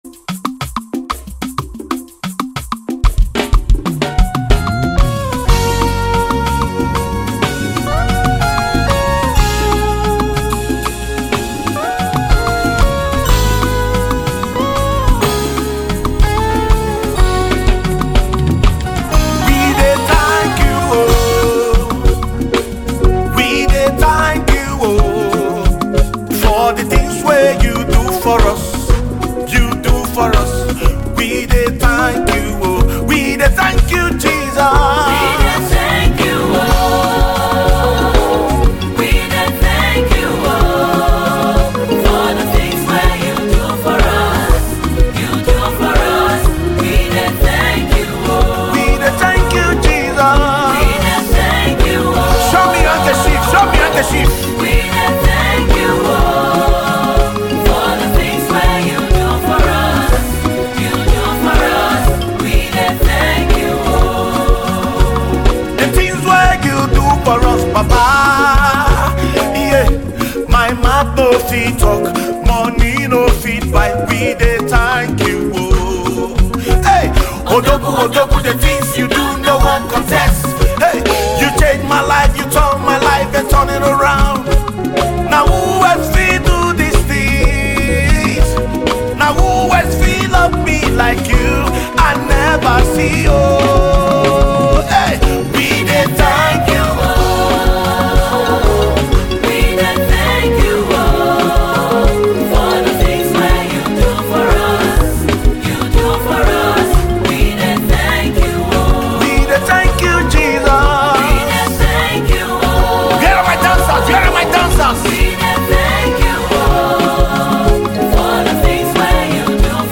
a joyful and spirit-lifting praise single
a heartfelt thanksgiving anthem